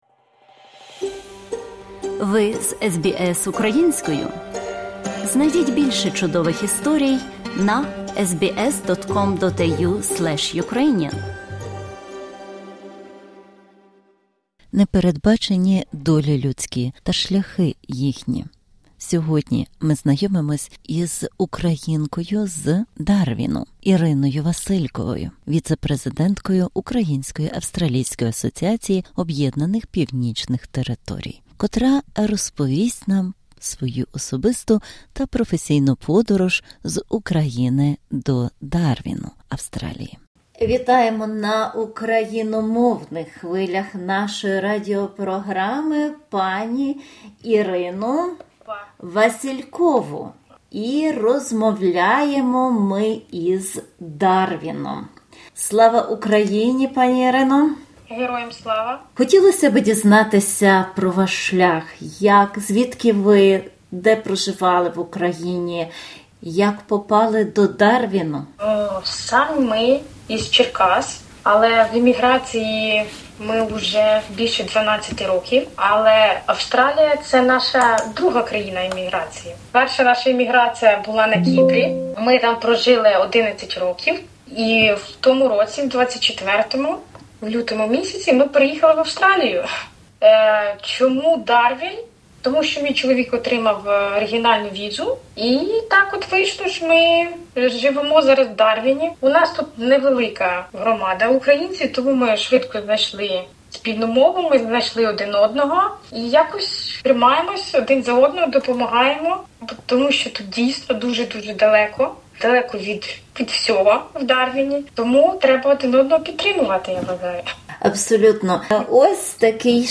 Розмова присвячена місії UAANT, викликам і можливостям для українців у Дарвіні та значенню їхнього першого офіційного представництва на XXIX з'їзді Союзу Українських Організацій Австралії (AFUO) у Мельбурні 14 -16 березня 2025-го року.